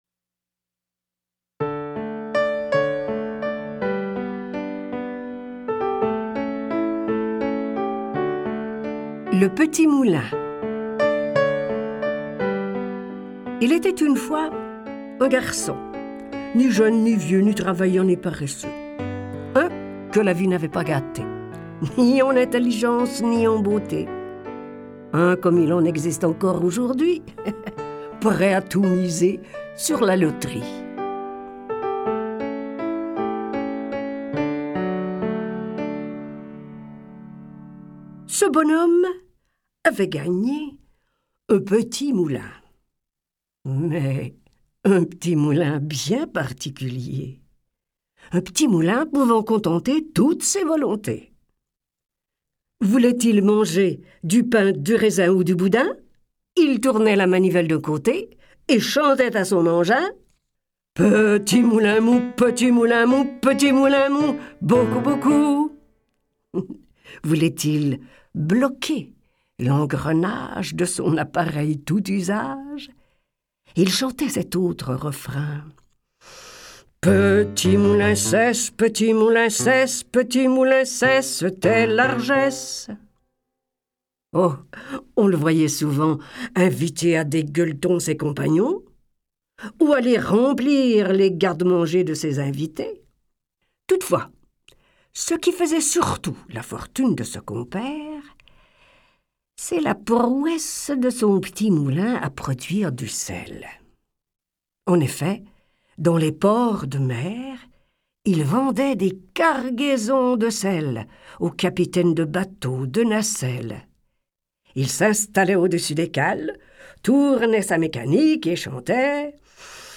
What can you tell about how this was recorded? Elle redonne la parole à nos contes.